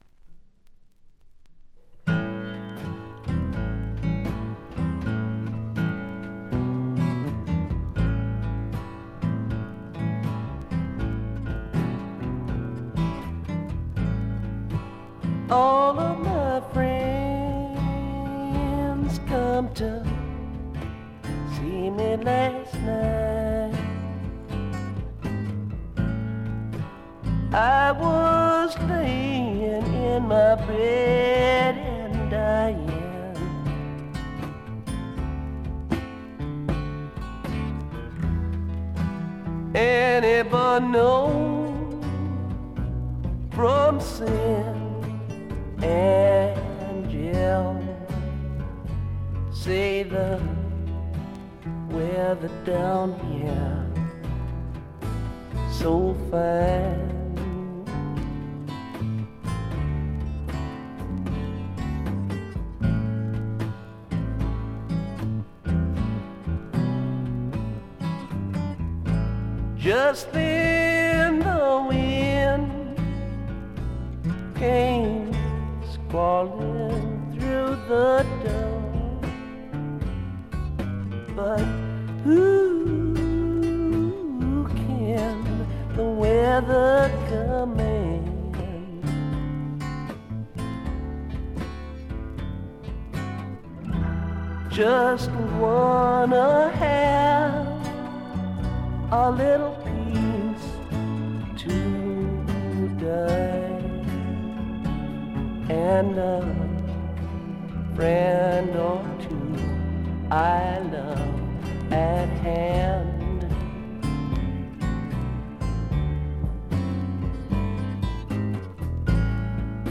部分試聴ですが、ほとんどノイズ感無し。
試聴曲は現品からの取り込み音源です。